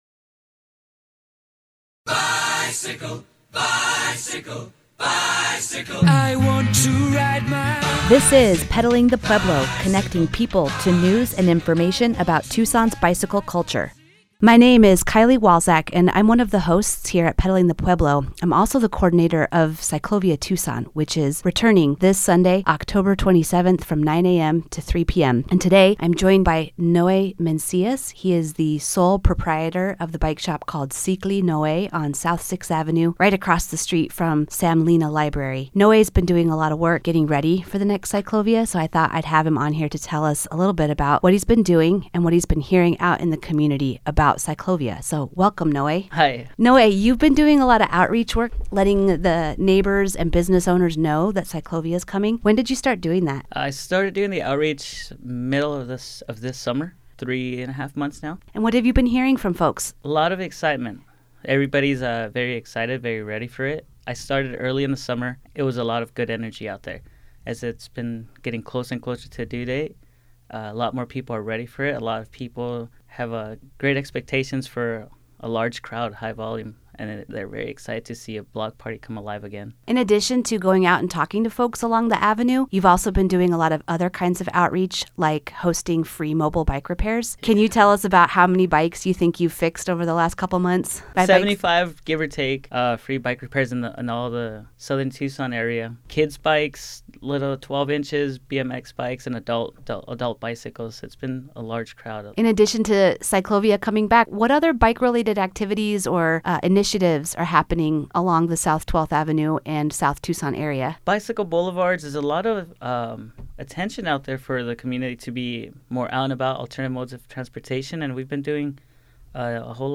Pedaling the Pueblo is a mini-program and podcast that connects people to news and information about Tucson’s bicycle culture.